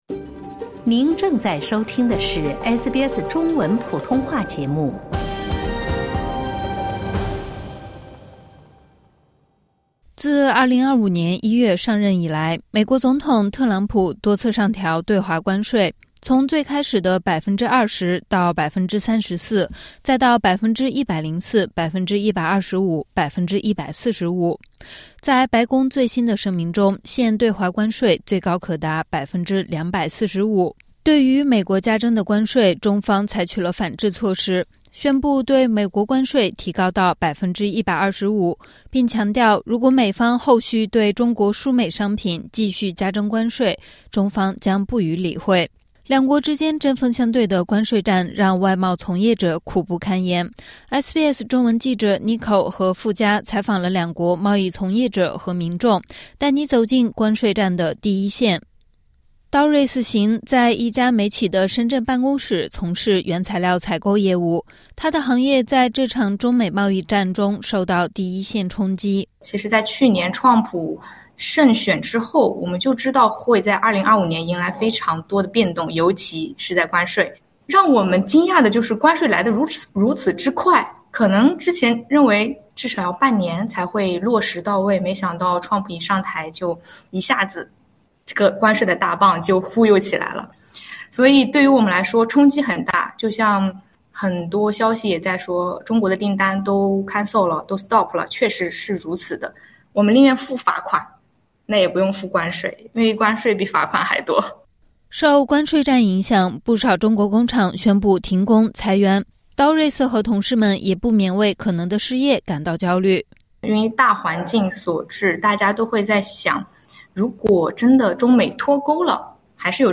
SBS中文采访了两国一线贸易从业者和民众。